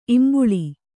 ♪ imbuḷi